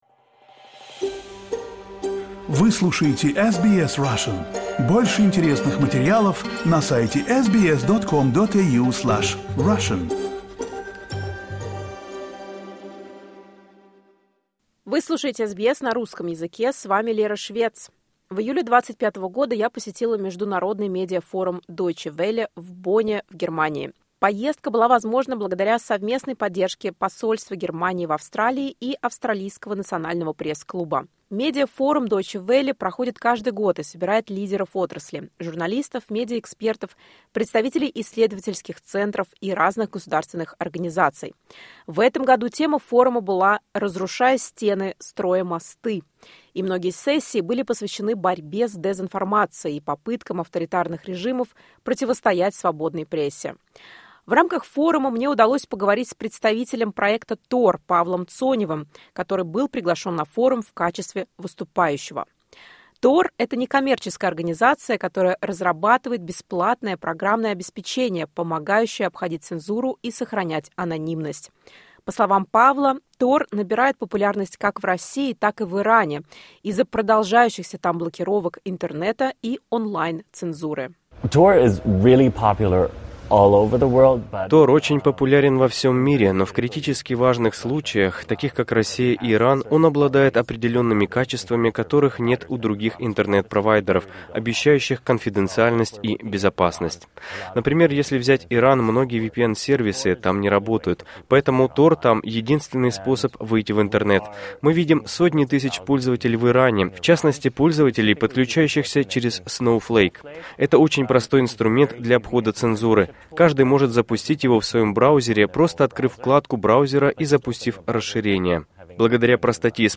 Это интервью было записано во время исследовательской поездки на DW Global Media Forum при содействии посольства Германии в Австралии и Национального пресс-клуба Австралии.